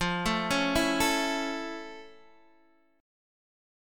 F+M7 chord